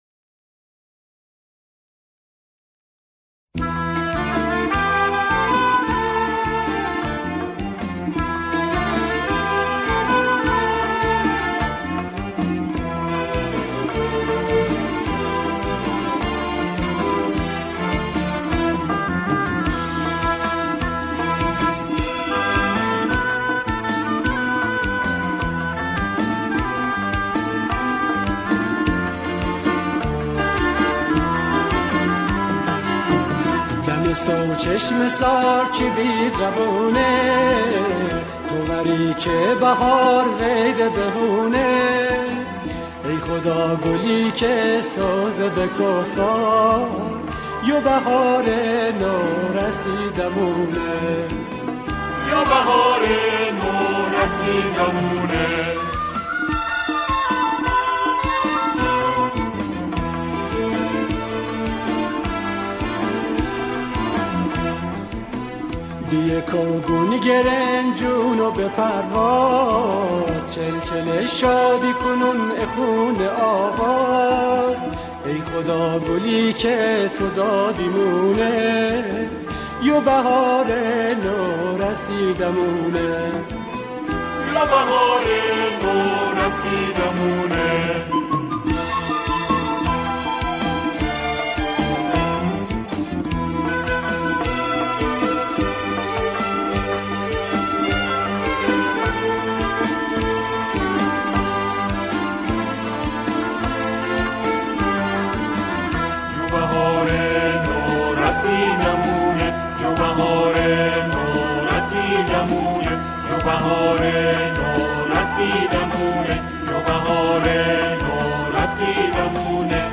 همراهی گروه کر